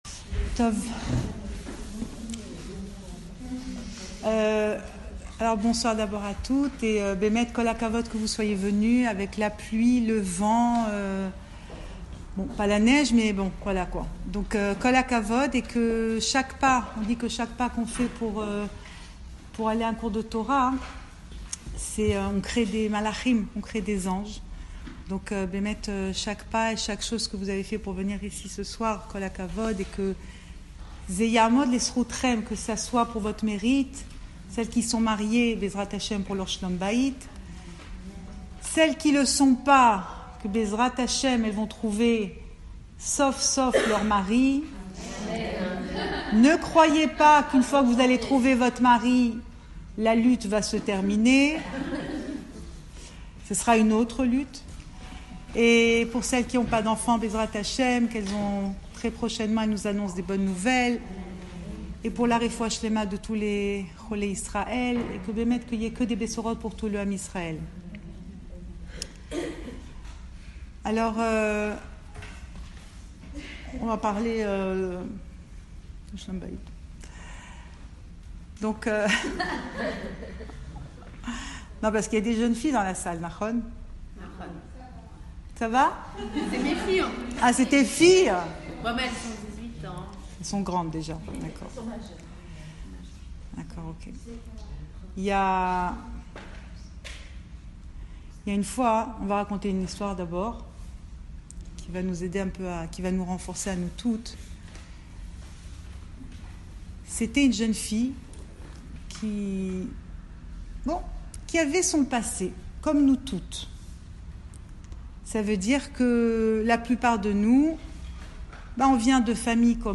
Cours audio Emouna Le coin des femmes Pensée Breslev - 6 décembre 2017 17 décembre 2017 Tel Aviv : il faut aller à contre courant… Enregistré à Tel Aviv